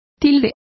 Complete with pronunciation of the translation of accents.